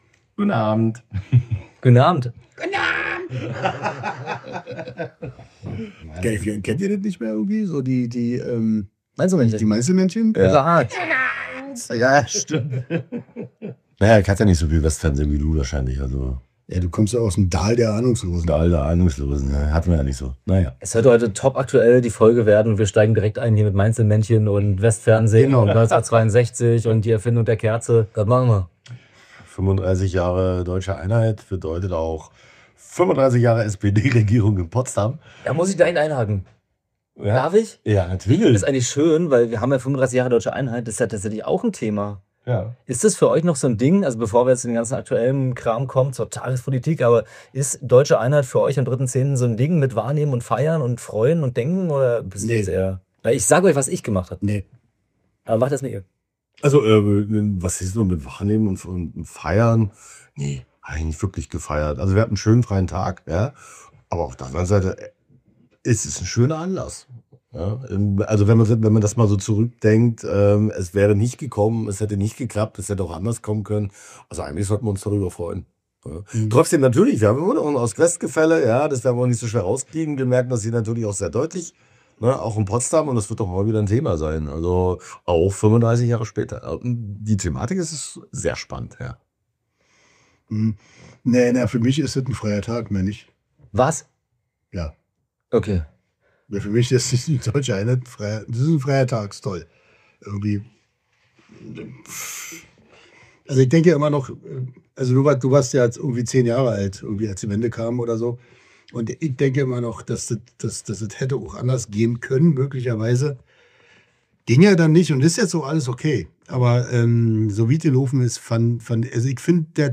Die Mainzelmännchen im Wandel der Nachwendezeit! Zwei Jungspunde (U70) schwadronieren über Ost-Westkonflikte und und Potsdams gestandenster Anekdotenveteran versteht das alles nicht.